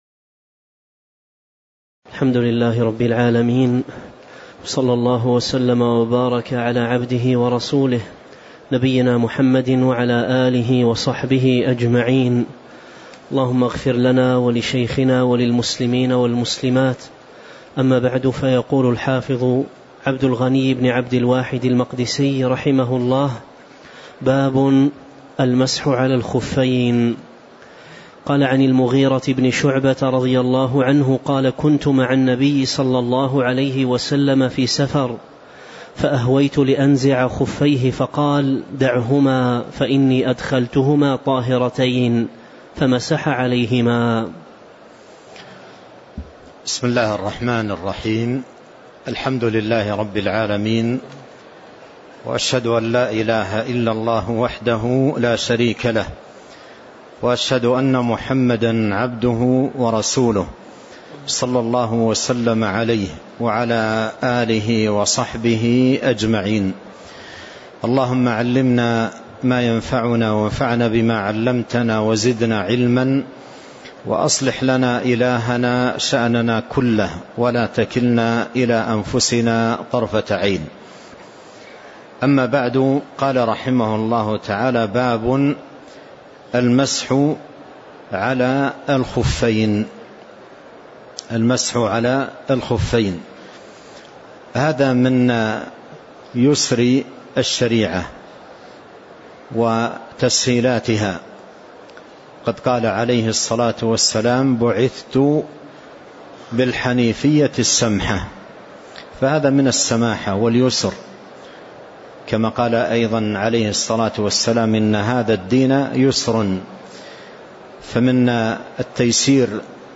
تاريخ النشر ١٠ ربيع الأول ١٤٤٤ هـ المكان: المسجد النبوي الشيخ